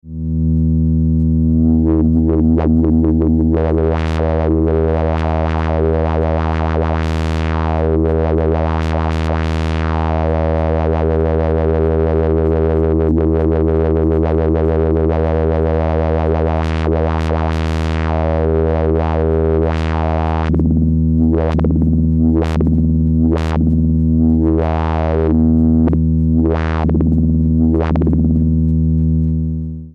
Sound example of the magnet-hand-controlled filter
is a demonstration of the filter being controlled by hand using a magnet on a metal strip that can be bended down (at the end of the soundexample the strip is hit to create vibration).
filtchoke-magnet-handcontrol.mp3